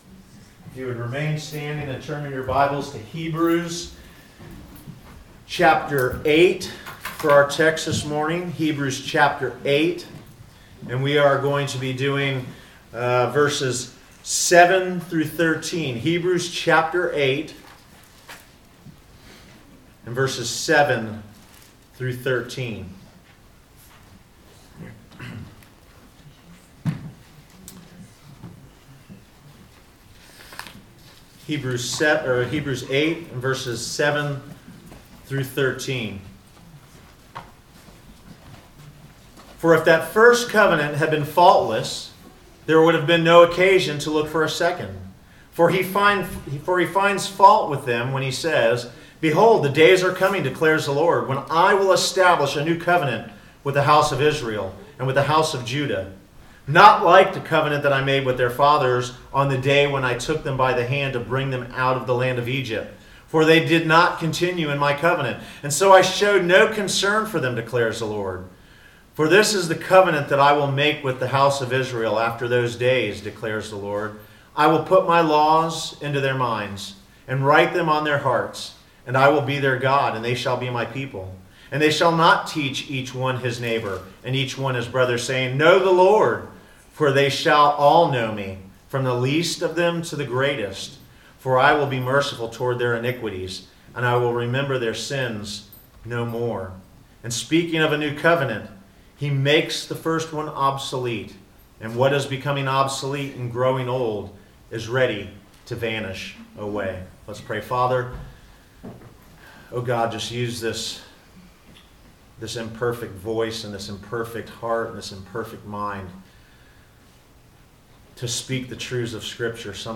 Service Type: Sunday Morning Topics: God's Glory , New Covenant , Old Covenant